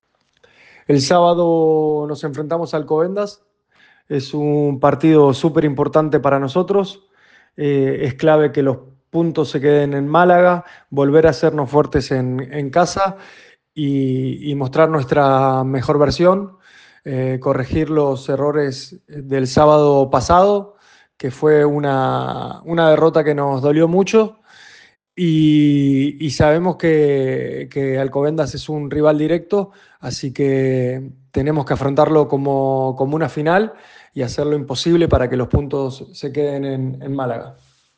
Manifestaciones del jugador del Trops Málaga